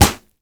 punch_head_weapon_bat_impact_04.wav